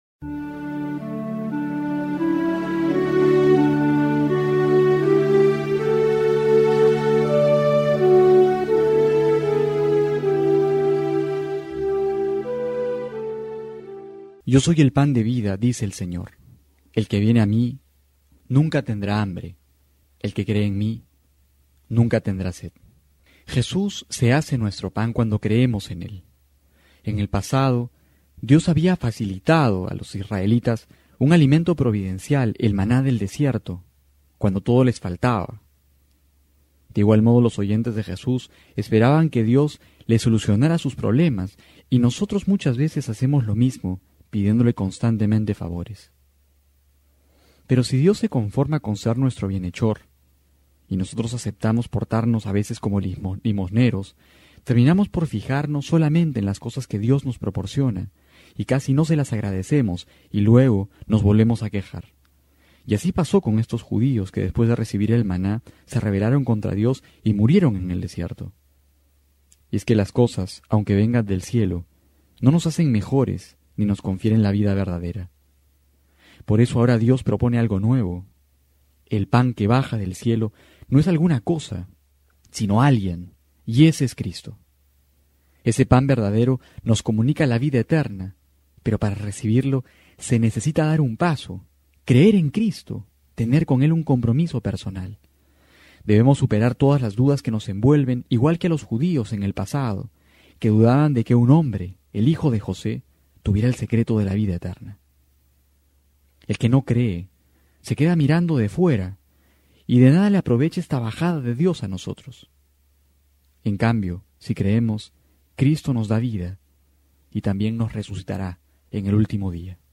Homilía para hoy: Juan 6,30-35
abril24-12homilia.mp3